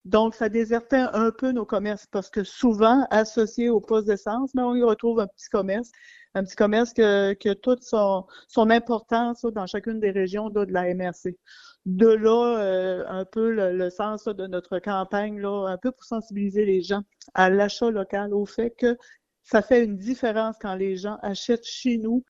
Voici la préfète suppléante et mairesse de Matapédia, Nicole Lagacé :